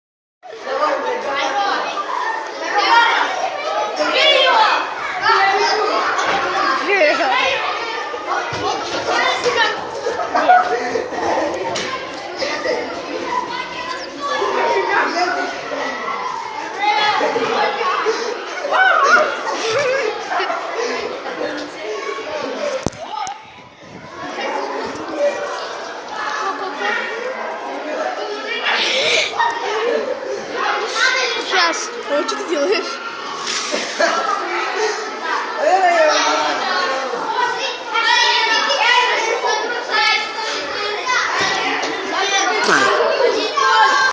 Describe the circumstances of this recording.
• Quality: High